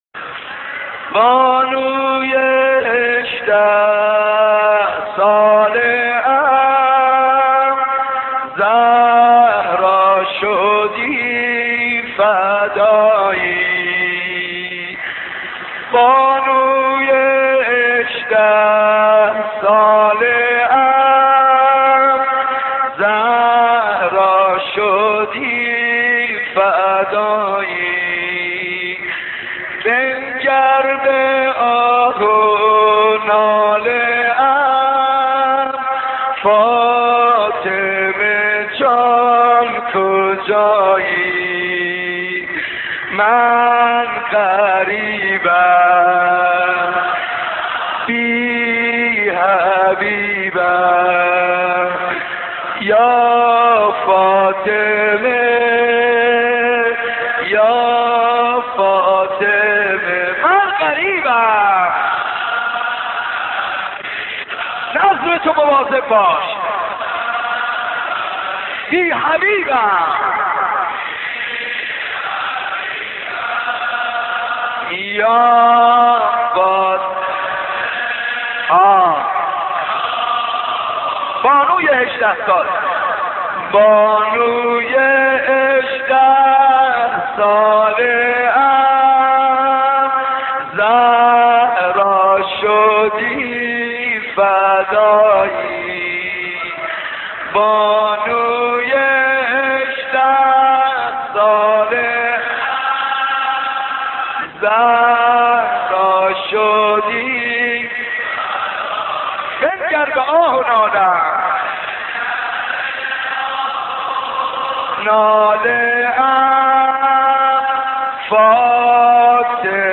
دانلود مداحی بانوی هجده ساله ام زهرا شدی فدایی - دانلود ریمیکس و آهنگ جدید
مجلس نوحه‌خوانی به مناسبت شهادت حضرت زهرا(س) توسط حاج منصور ارضی (18:34)